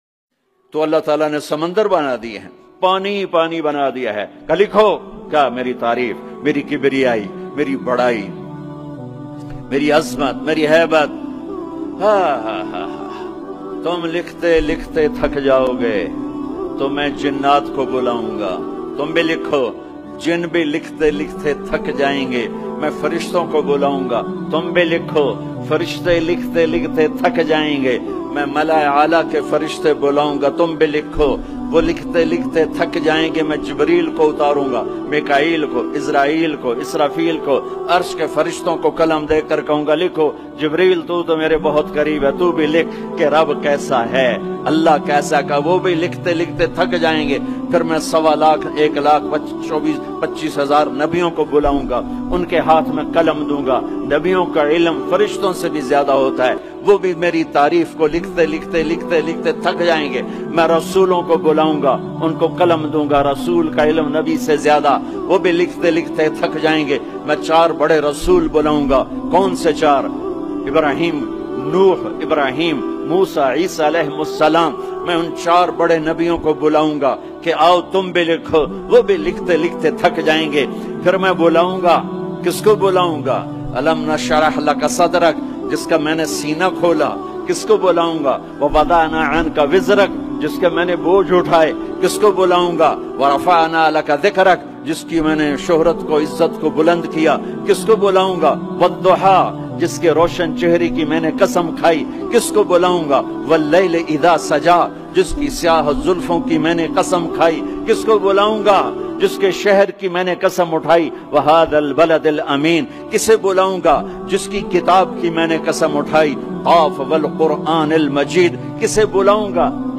Allah Rab Ul Izzat Ki Tareef Bayan Tariq Jameel